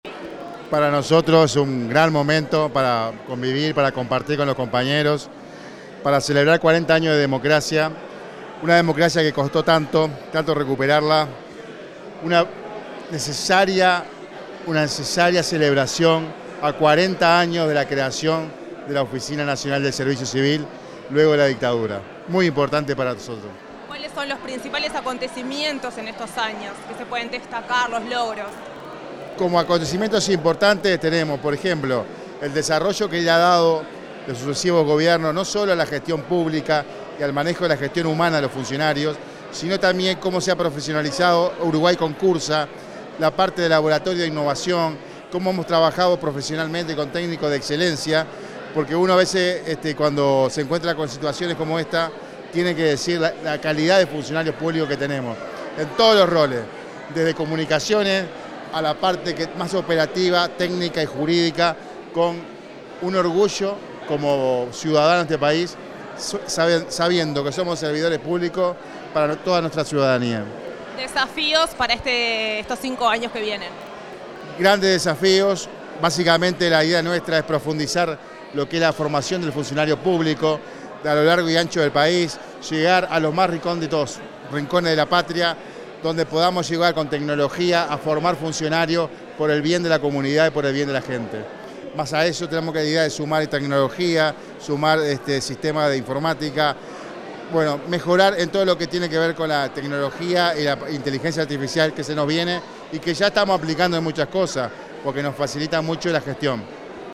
Declaraciones del director de la ONSC, Sergio Pérez
El director de la Oficina Nacional del Servicio Civil (ONSC), Sergio Pérez, dialogó con la prensa tras la ceremonia por los 40 años de esa repartición